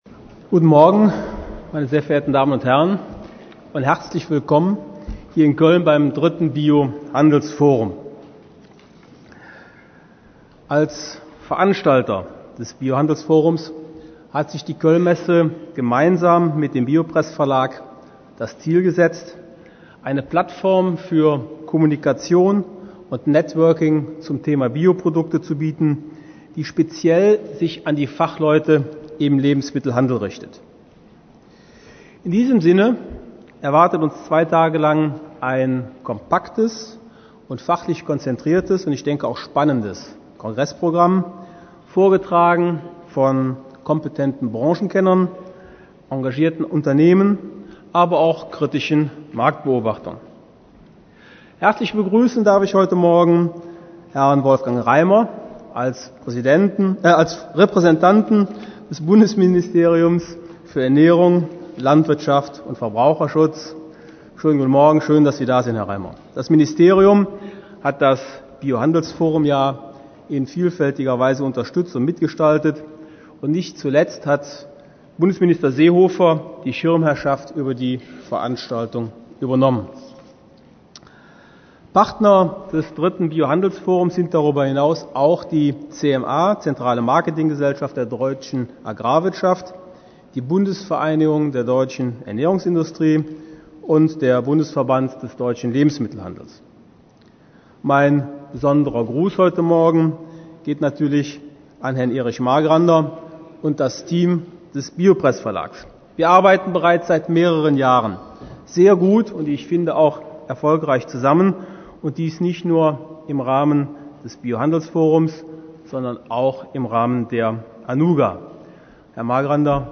3bhf_2008_09_16_1_eroeffnung.mp3